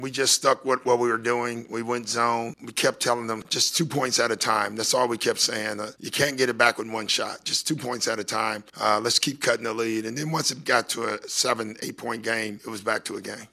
Bucks coach Doc River talked about rallying for the win after falling well behind in the first quarter.